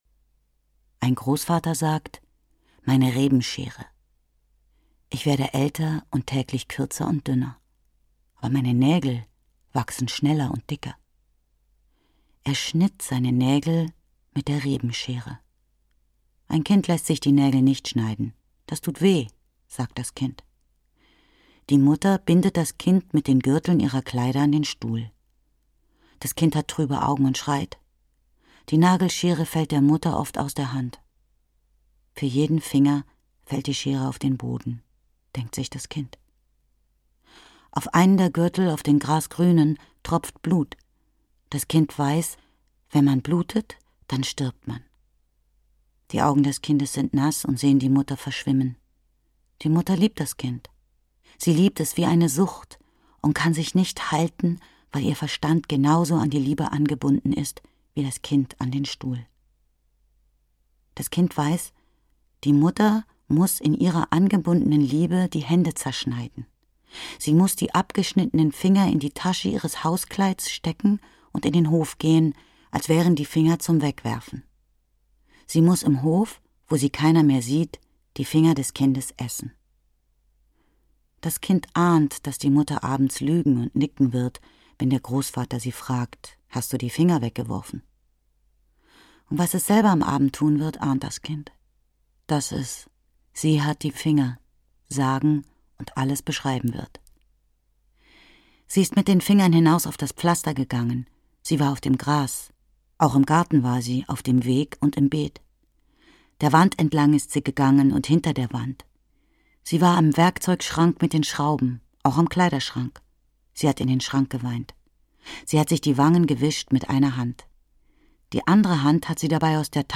Herztier - Herta Müller - Hörbuch